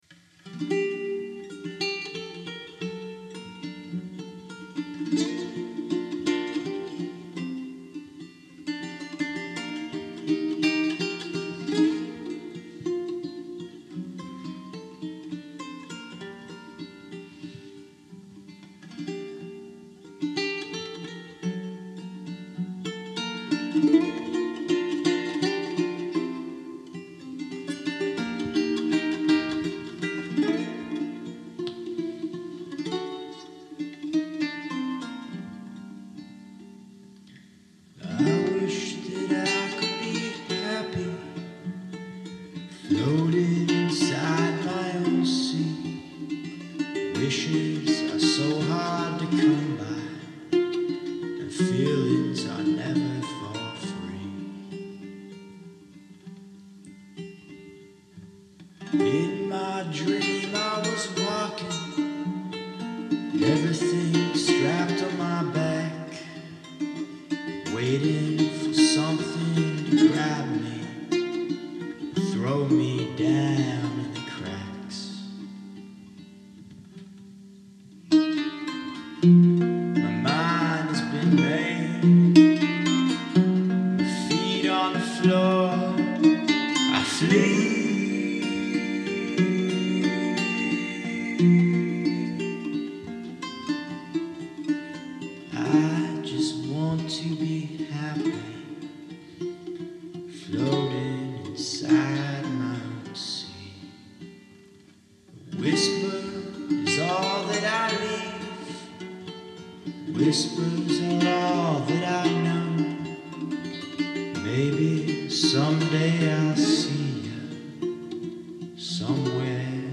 Audio samples of my ronroco: